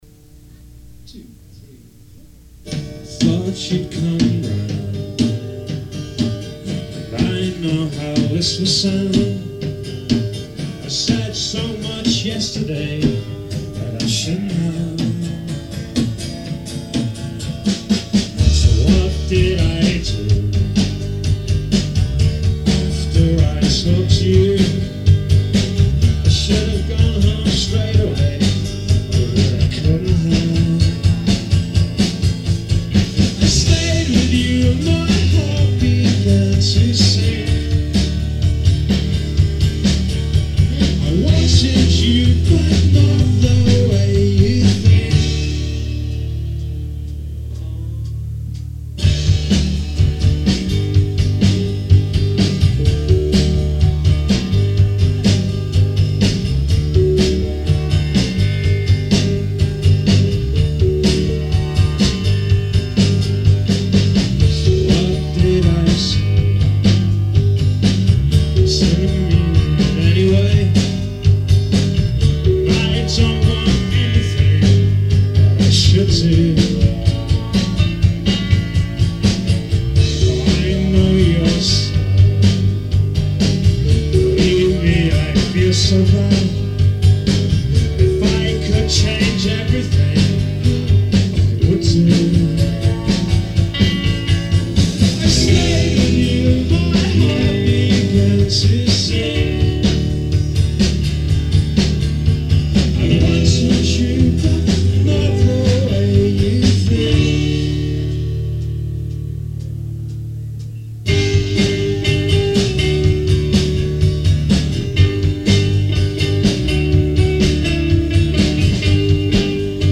Hoboken, NJ